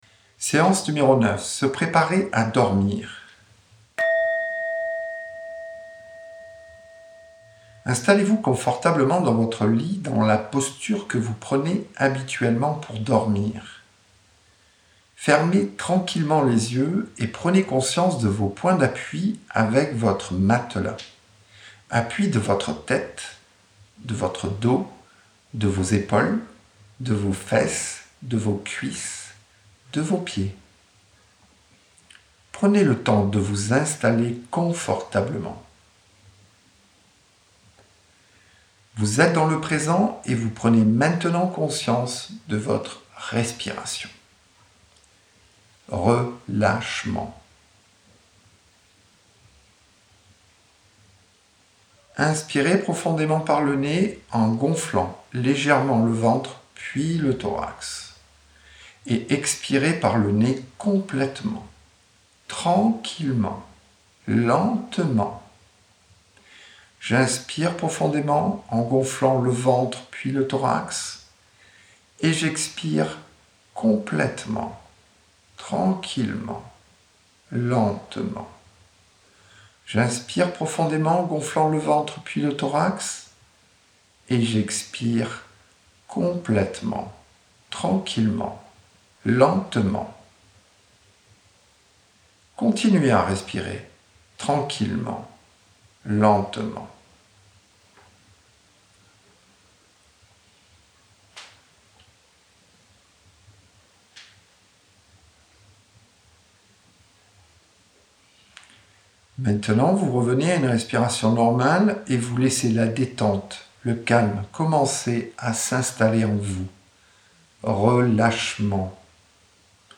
9 séances de méditation, de 5 à 9 minutes, sur les thèmes suivants :